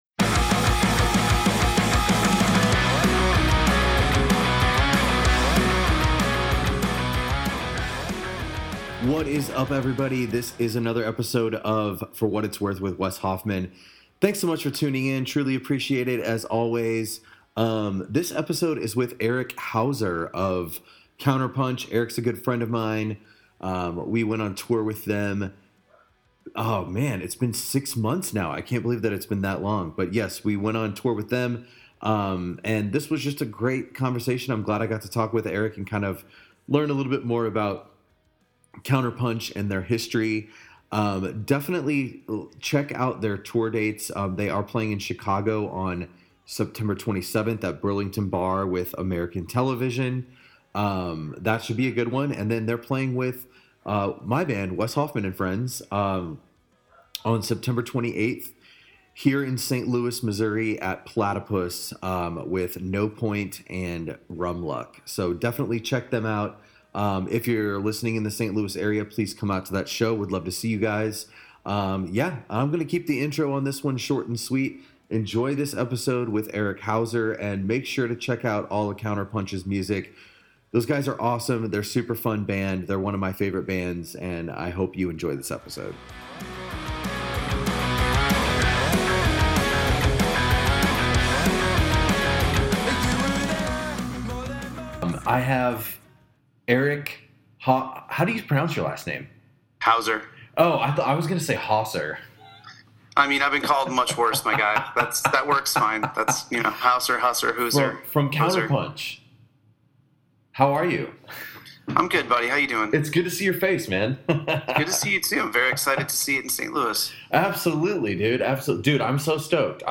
We had a great conversation about Counterpunch's beginnings, the Chicago music scene, playing shreddy punk, and a little bit about Chicago sports.